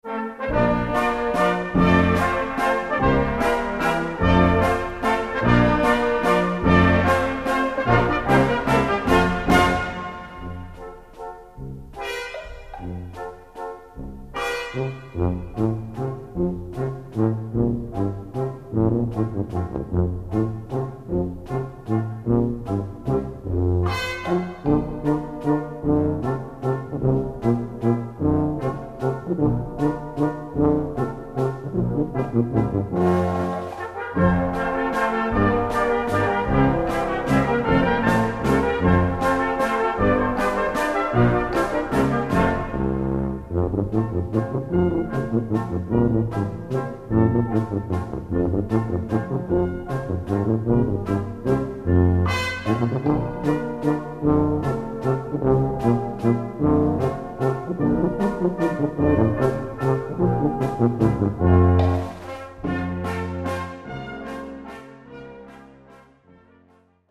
Répertoire pour Brass band - Tuba et Brass Band